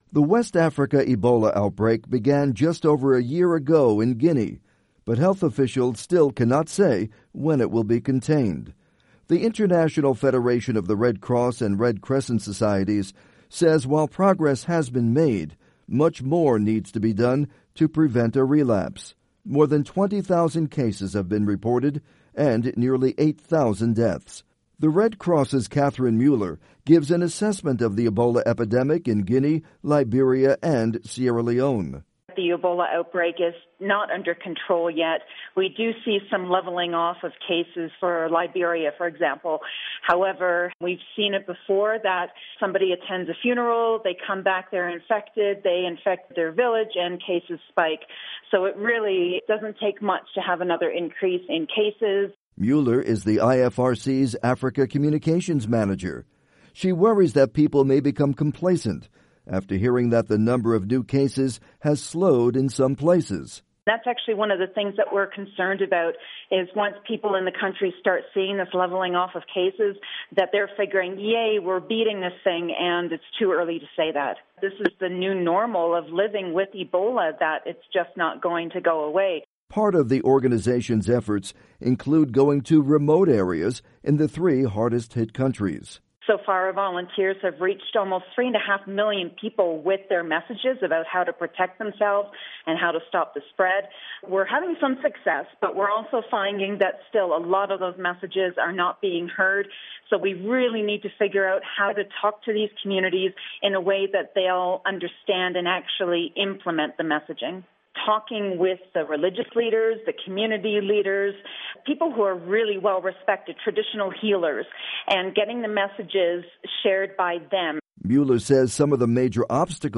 report on Red Cross efforts against Ebola